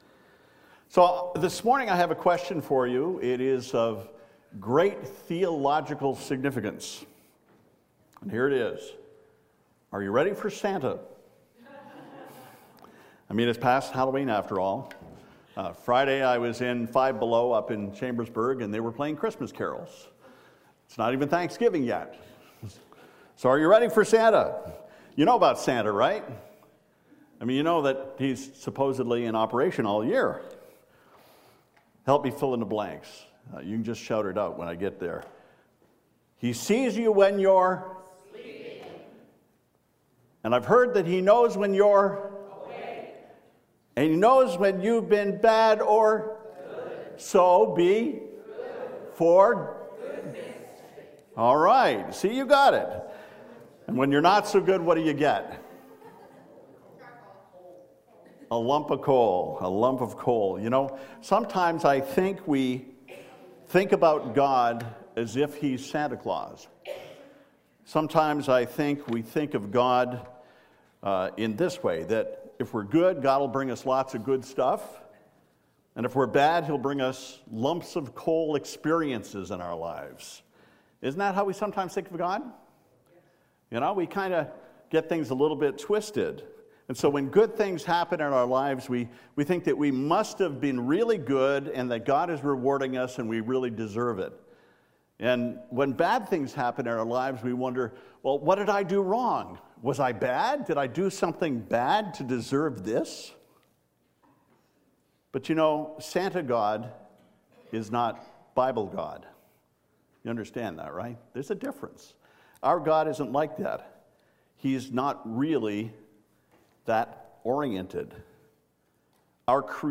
Sermon-Goodness-1.mp3